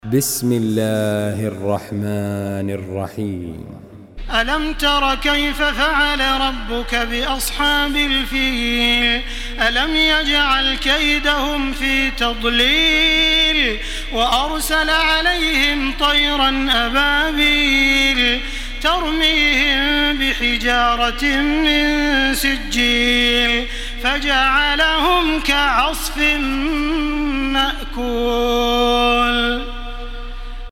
سورة الفيل MP3 بصوت تراويح الحرم المكي 1434 برواية حفص
مرتل